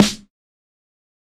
SN - Perfect Preemo.wav